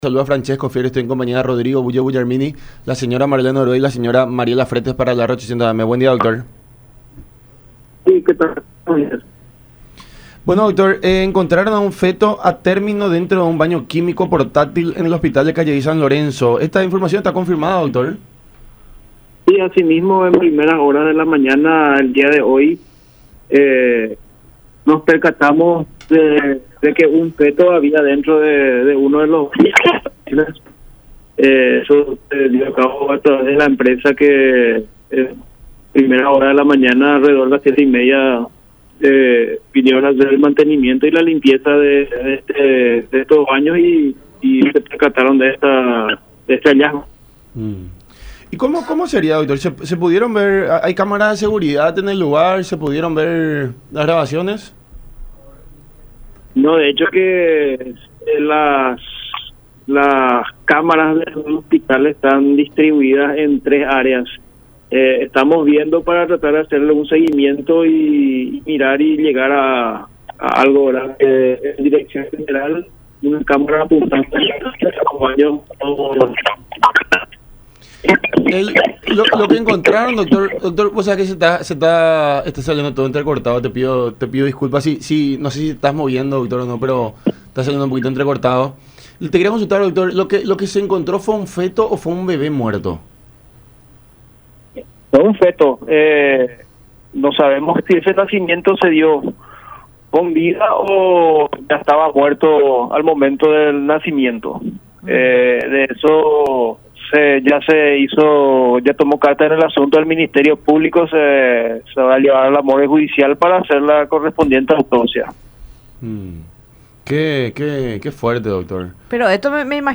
en diálogo con La Unión Hace La Fuerza por Unión TV y radio La Unión.